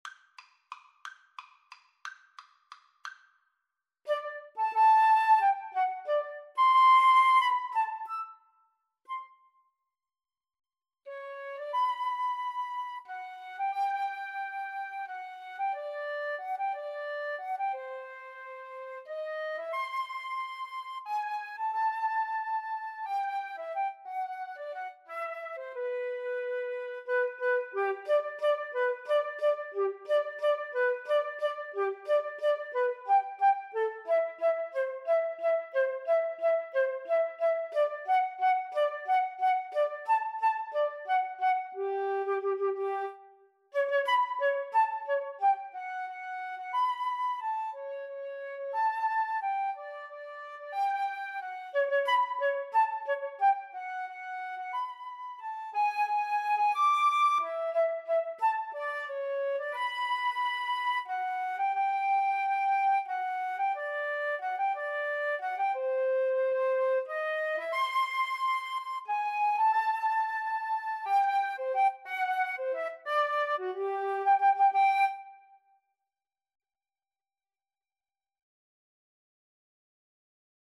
~ = 180 Tempo di Valse
Classical (View more Classical Flute Duet Music)